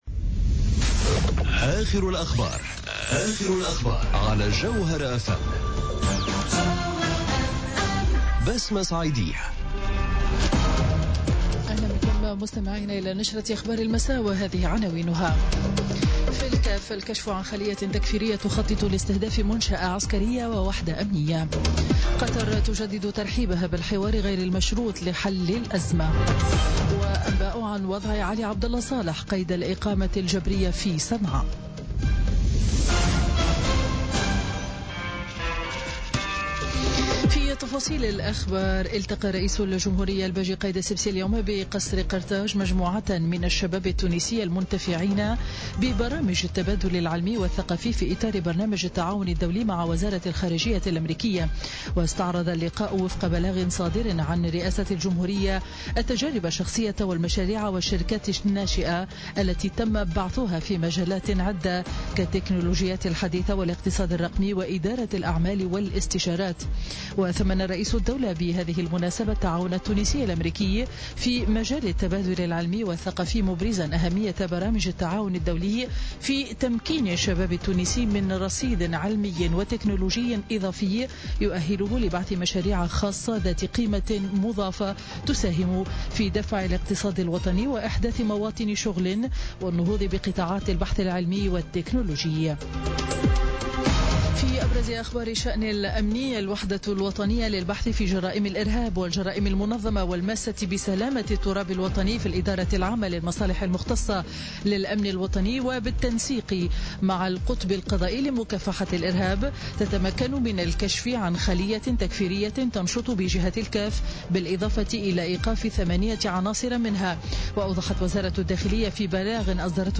نشرة أخبار السابعة مساء ليوم الأربعاء 30 أوت 2017